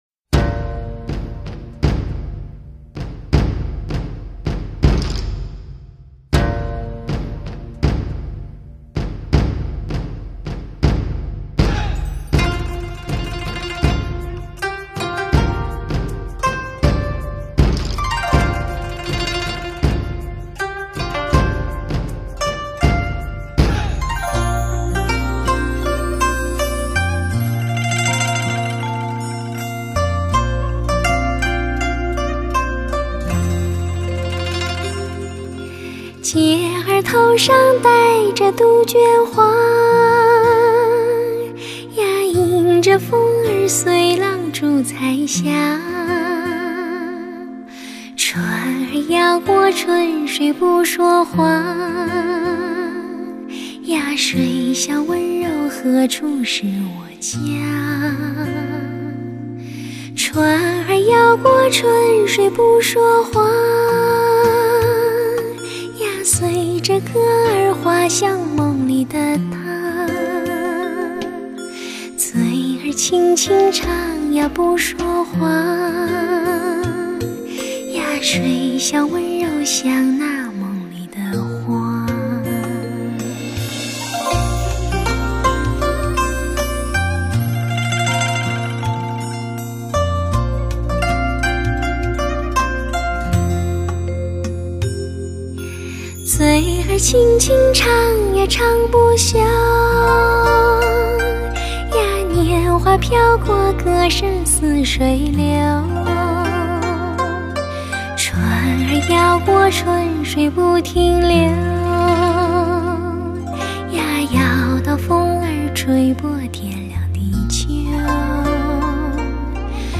Beautiful Chinese Music, Traditional
beautiful-chinese-music32traditional.mp3